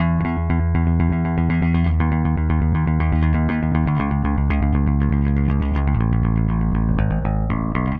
Power Pop Punk Bass 03.wav